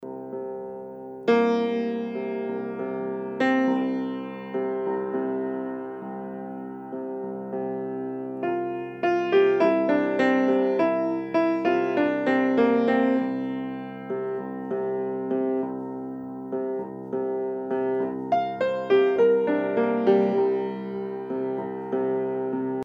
(Gelöst)Flügelmikrofonierung: Phasenauslöschungen in der DAW richten?
Zum Vergrößern anklicken.... also ich glaube man kann deine aufnahmen gut "retten", wenn man (je nach bedarf) mit der änderung der polarität einer seite arbeitet und insgesamt die stereobreite etwas einschränkt. dann klingt es direkt viel besser: